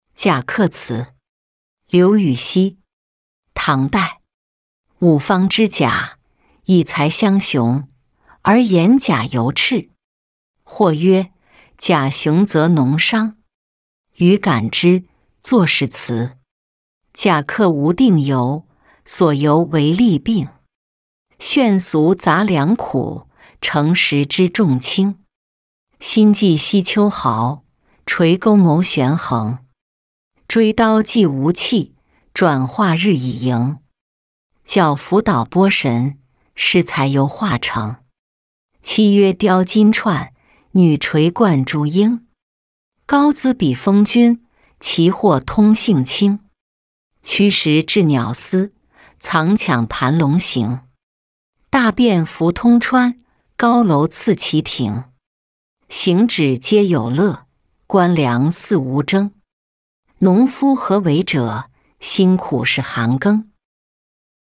贾客词-音频朗读